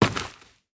Dirt footsteps 9.wav